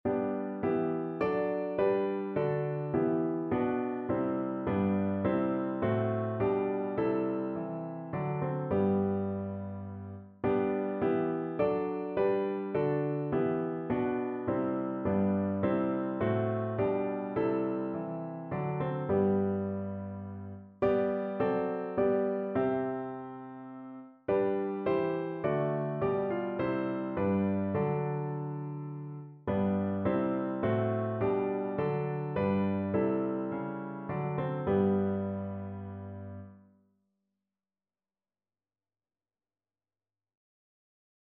Notensatz 1 (4 Stimmen gemischt)
• gemischter Chor [MP3] 645 KB Download